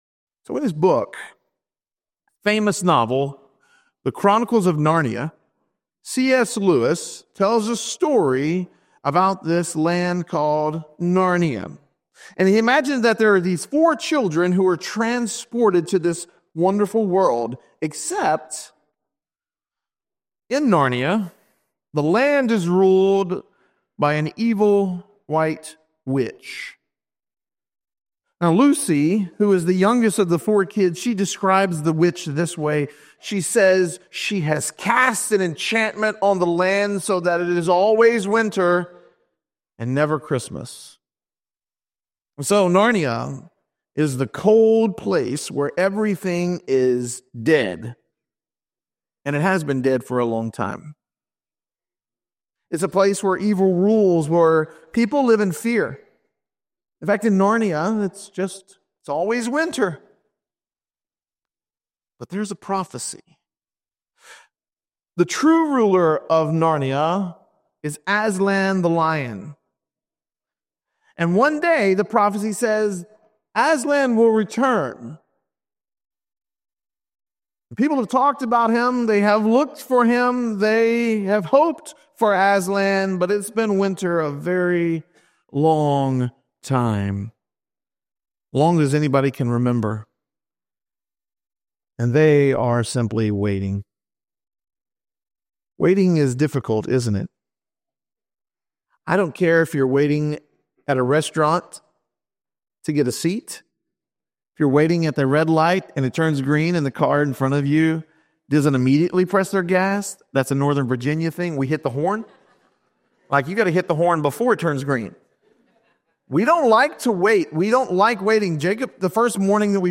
In this sermon, we reflect on living in the "Kingdom in the Middle"—between Jesus' birth and His return. As we wait for Christ’s second coming, we are called to live with urgency, hope, and the awareness that the King who came once will return to reign forever.